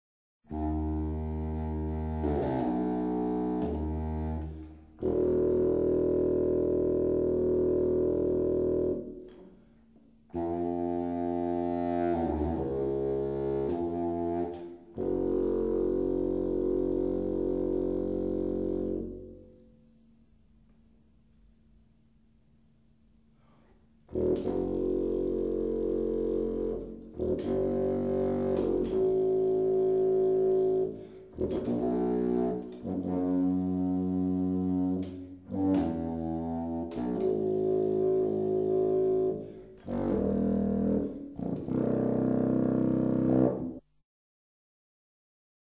17 Contrafagot.wma